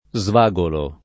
svagolare v.; svagolo [ @ v #g olo ]